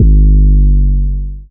DDW6 808 7.wav